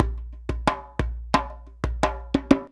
089DJEMB05.wav